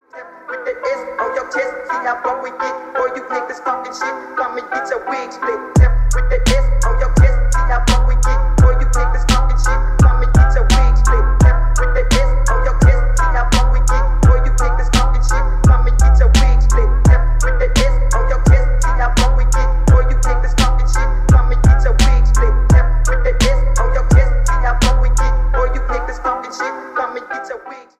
• Качество: 320 kbps, Stereo
Рэп и Хип Хоп
громкие